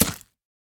Minecraft Version Minecraft Version latest Latest Release | Latest Snapshot latest / assets / minecraft / sounds / block / pumpkin / carve1.ogg Compare With Compare With Latest Release | Latest Snapshot
carve1.ogg